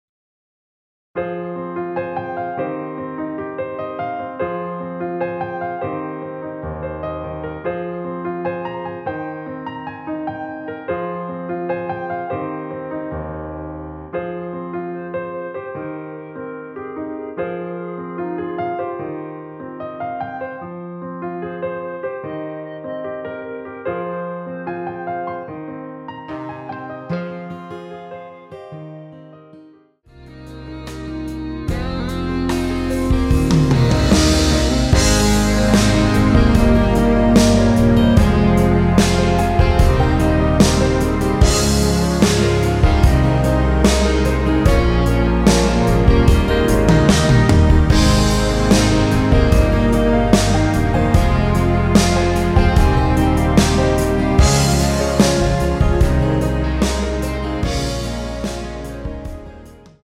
원키에서(-6)내린 멜로디 포함된 MR입니다.
Fm
앞부분30초, 뒷부분30초씩 편집해서 올려 드리고 있습니다.
중간에 음이 끈어지고 다시 나오는 이유는